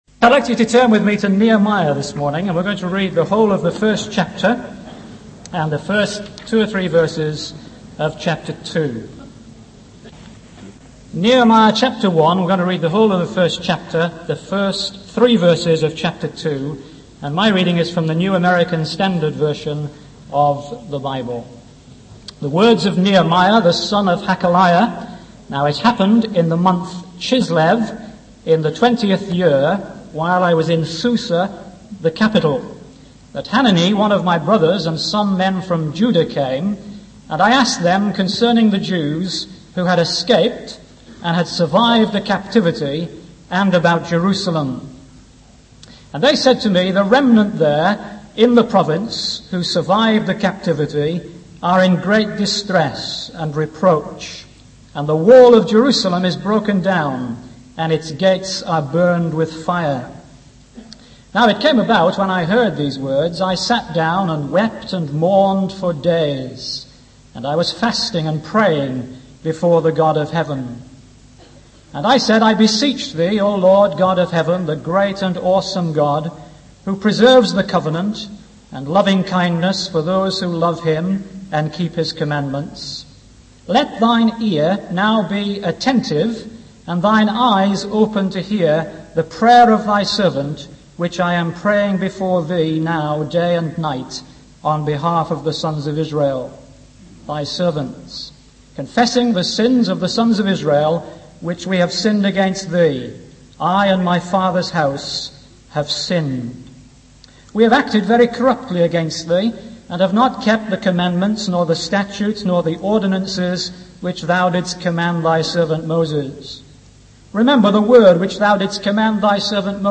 In this sermon, the speaker emphasizes the importance of having a clear objective in the work of God. He encourages the audience to think about their long-term and short-term goals in their Christian union, school union, or church responsibilities. The speaker references the example of Nehemiah, who assessed the damage to the walls of Jerusalem and took responsibility for rebuilding them.